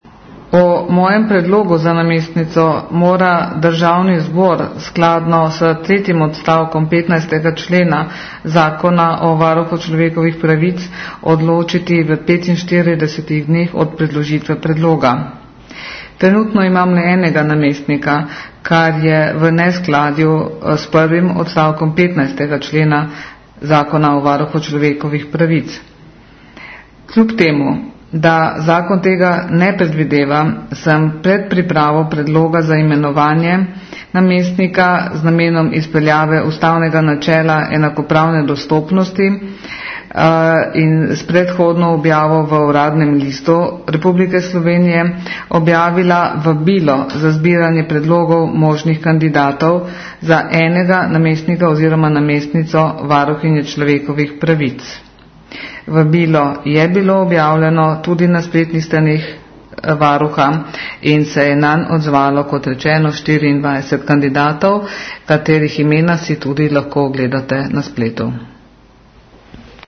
Zvočni posnetek izjave varuhinje ob posredovanju predloga za namestnico
1. del izjave - zvočni posnetek TUKAJ